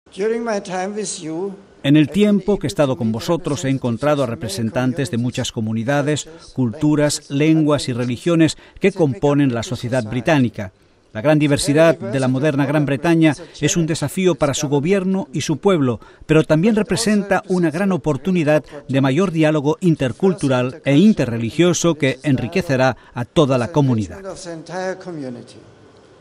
Domingo, 19 sep (RV).- Durante la ceremonia de despedida, que tuvo lugar en el aeropuerto internacional de Birmingham, el Papa, tras el discurso del primer ministro de Su Majestad, David Cameron, agradeció el intenso trabajo de preparación, tanto del Gobierno actual como del precedente, del servicio civil, de las autoridades locales y la policía, y de los numerosos voluntarios que pacientemente ayudaron a preparar los eventos de estos cuatro días.
DISCURSO DE DESPEDIDA